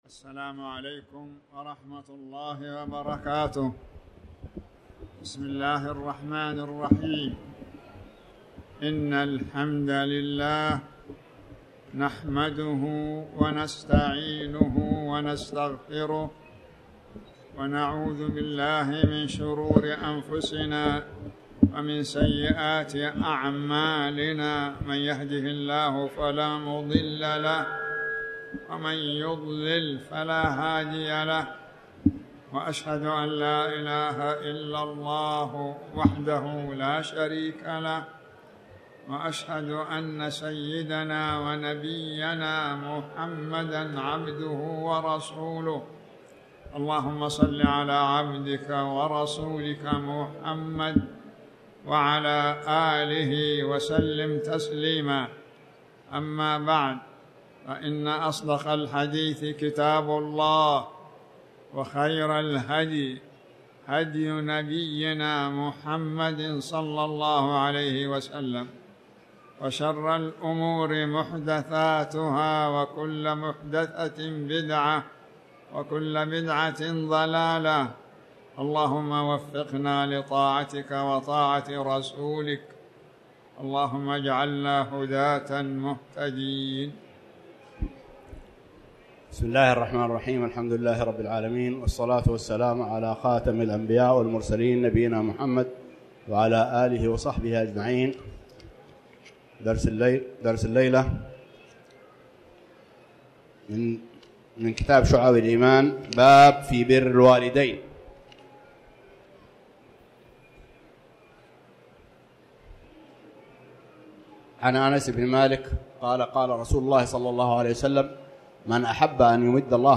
تاريخ النشر ٤ ذو القعدة ١٤٣٩ هـ المكان: المسجد الحرام الشيخ